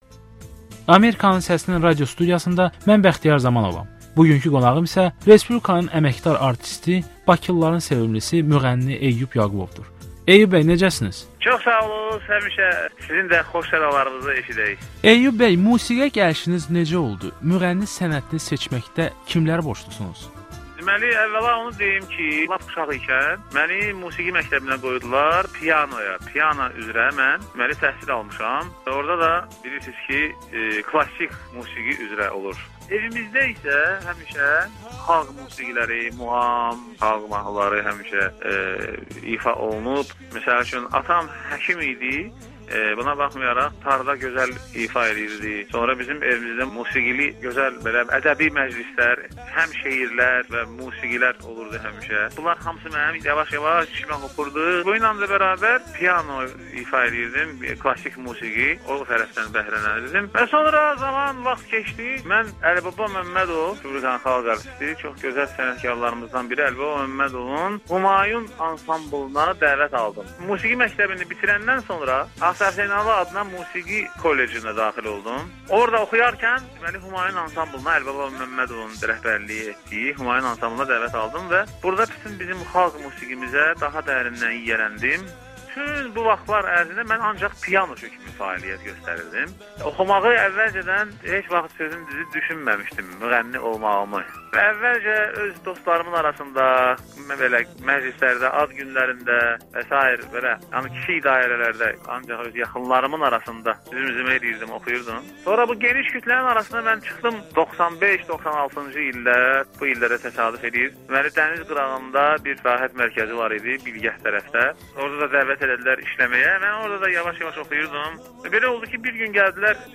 Respublikanın əməkdar artisti Eyyub Yaqubov Amerikanın Səsinə müsahibə verdi
Müğənni Eyyub Yaqubovla müsahibə